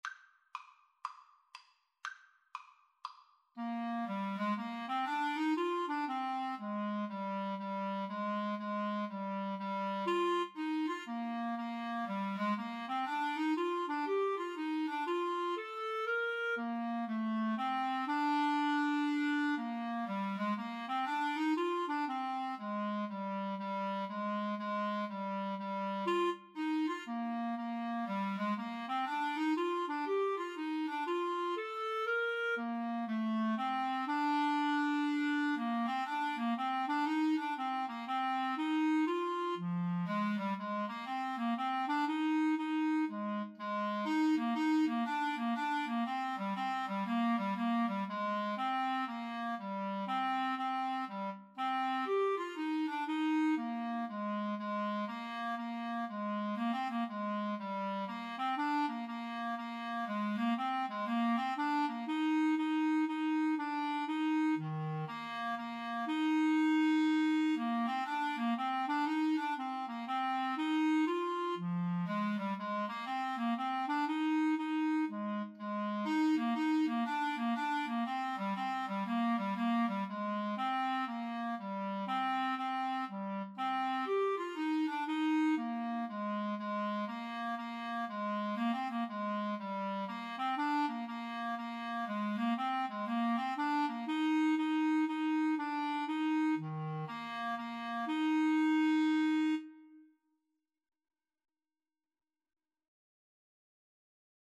Traditional (View more Traditional Clarinet Duet Music)